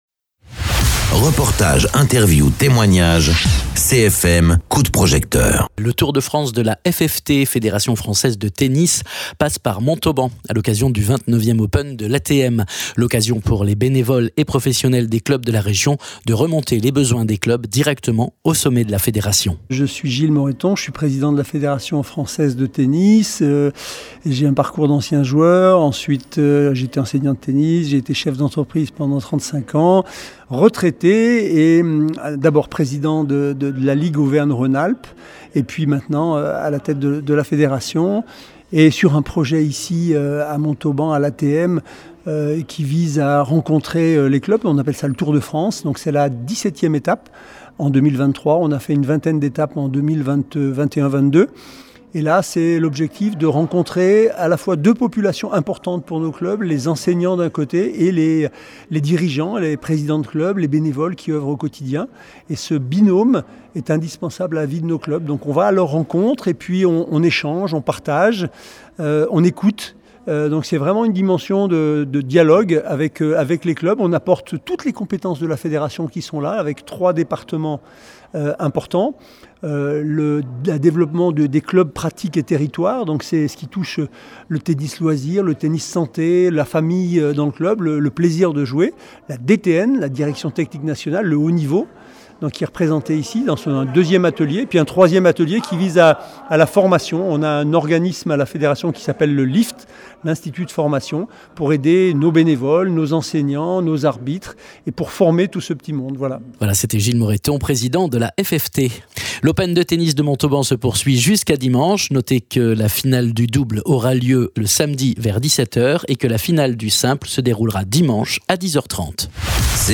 Interviews
Invité(s) : Gilles Morretton président de la FFT